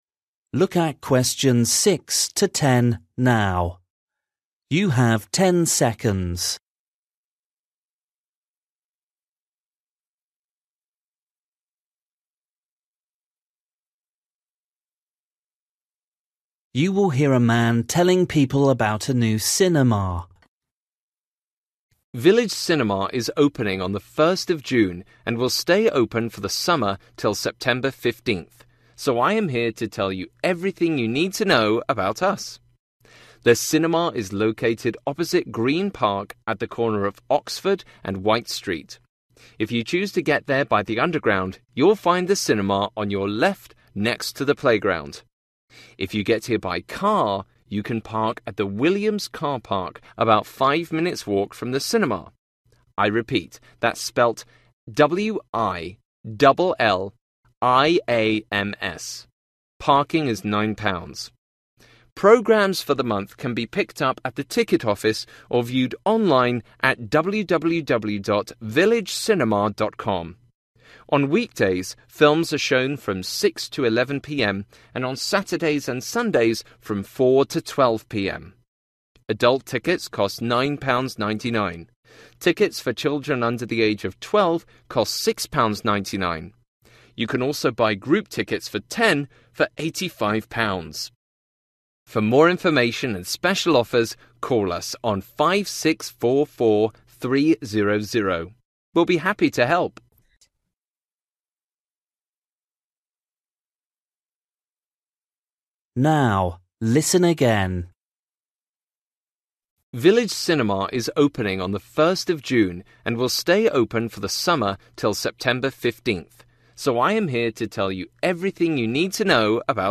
You will hear a man telling people about a new cinema.